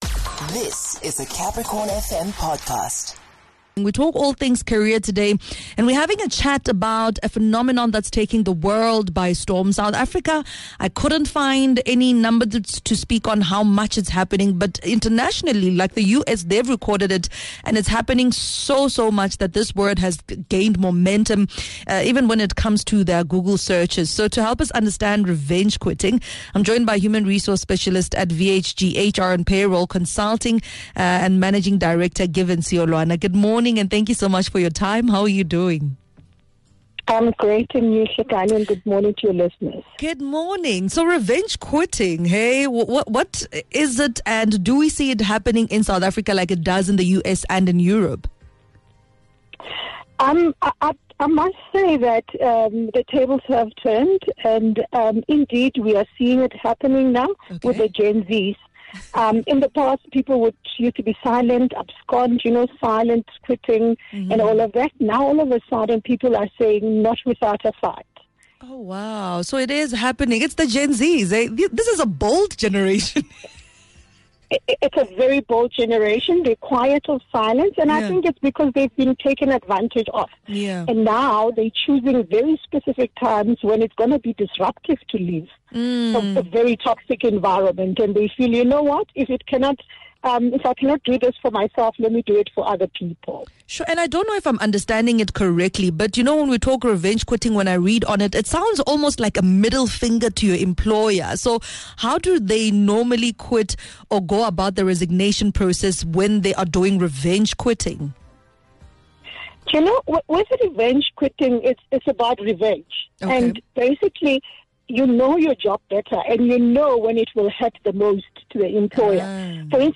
An informative conversation revenge quitting with HR specialist